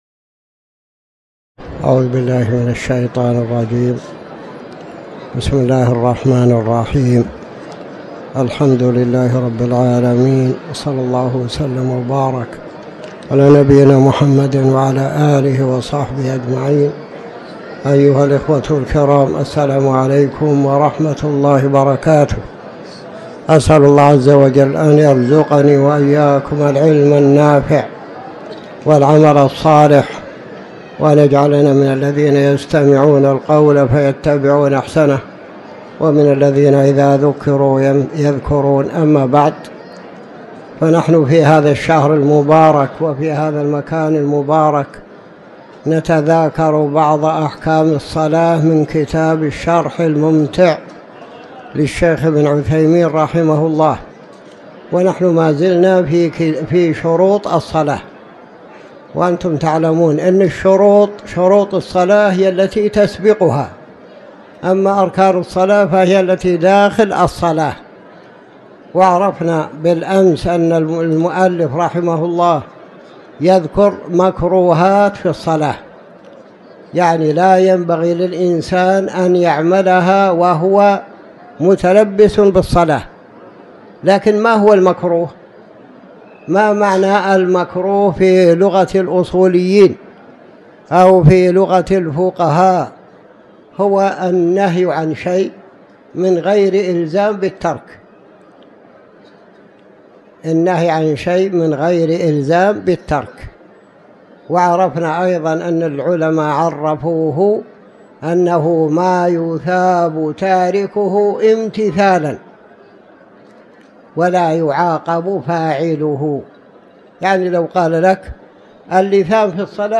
تاريخ النشر ١١ جمادى الآخرة ١٤٤٠ هـ المكان: المسجد الحرام الشيخ